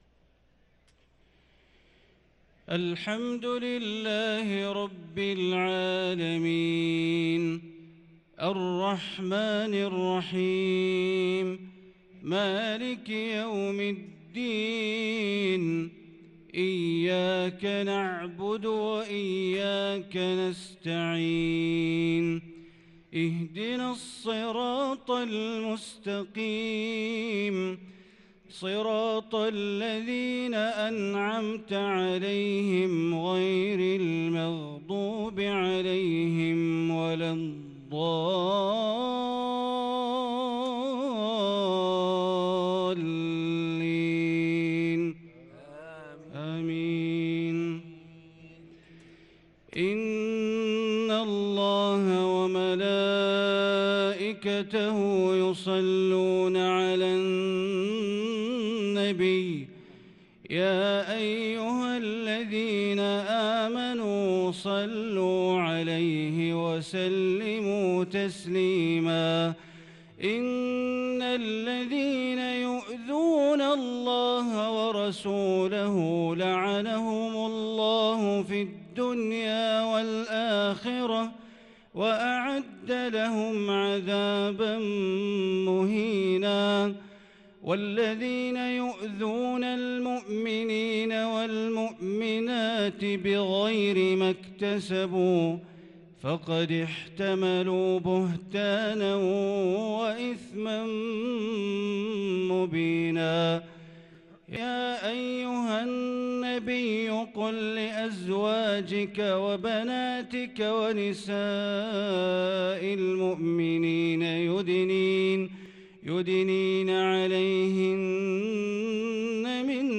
صلاة العشاء للقارئ بندر بليلة 6 رمضان 1443 هـ
تِلَاوَات الْحَرَمَيْن .